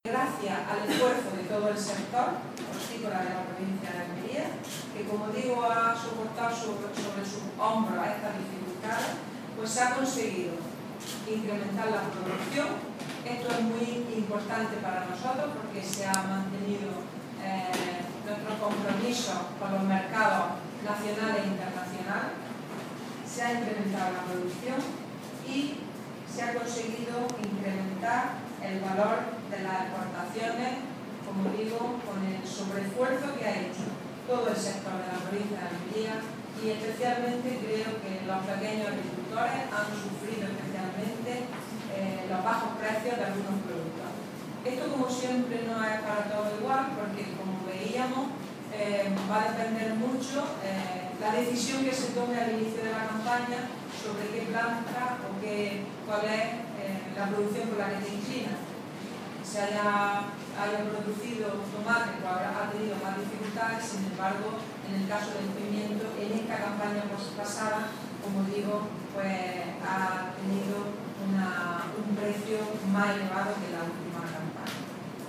Declaraciones de Carmen Ortiz sobre el avance de la campaña hortofrutícola 2015-2016 en Almería